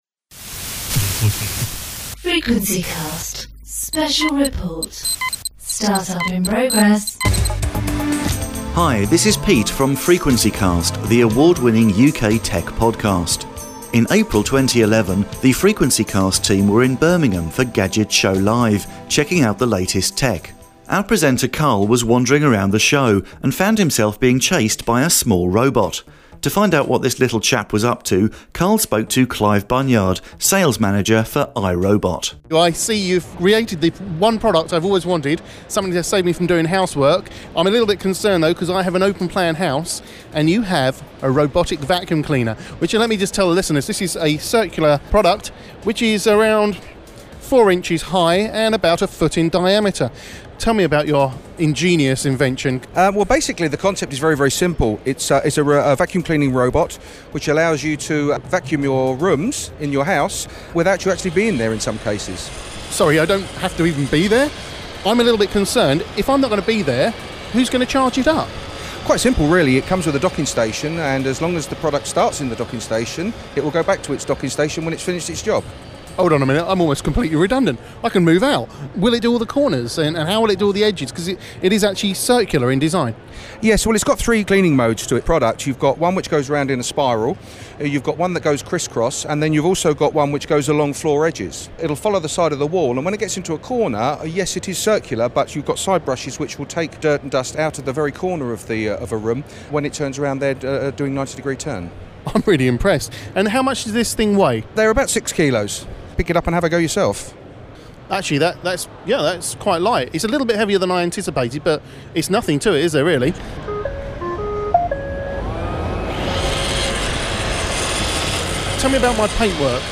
As part of our coverage of Gadget Show Live 2011, we took a closer look at the iRobot Roomba.
Hands on with the iRobot Roomba